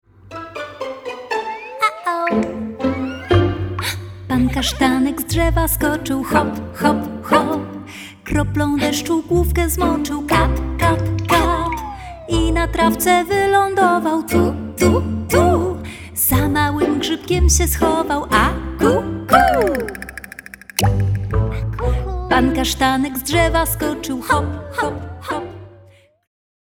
to radosna, łatwa do nauki piosenka